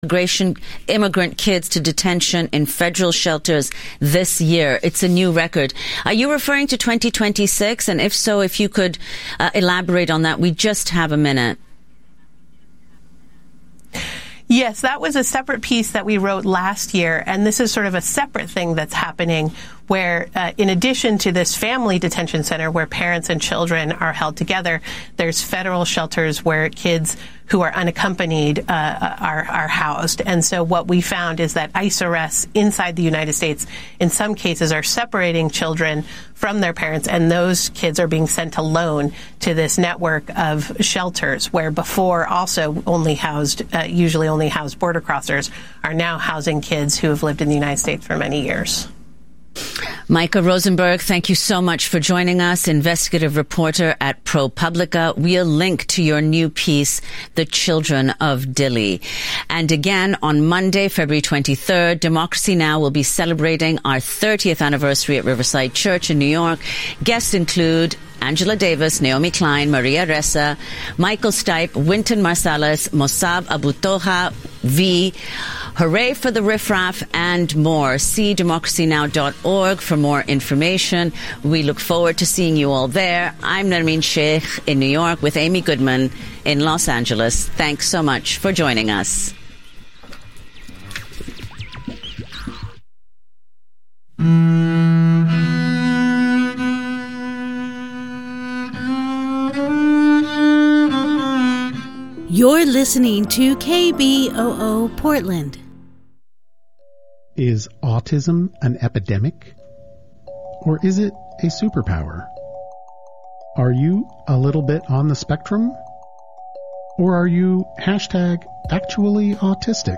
Evening News on 02/10/26
Hosted by: KBOO News Team